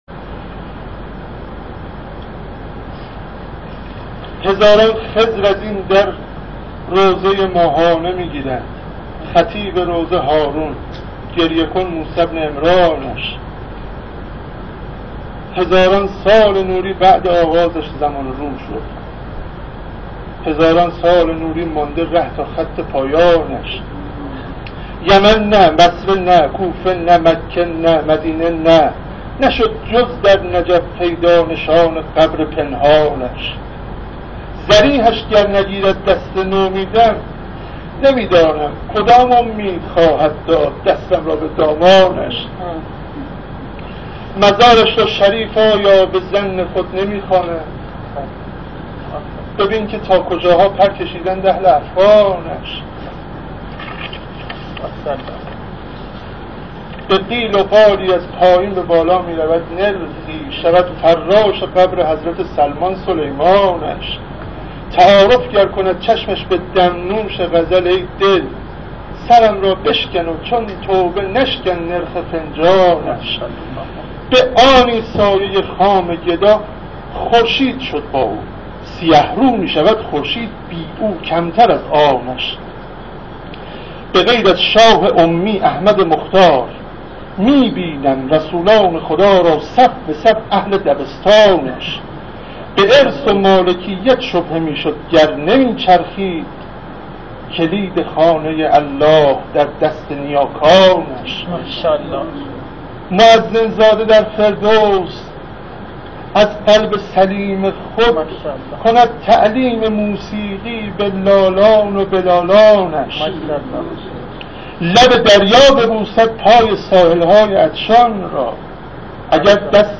در ادامه متن اشعار و صوت شعر خوانی شعرا را مشاهده می کنید: